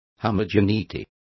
Also find out how homogeneidad is pronounced correctly.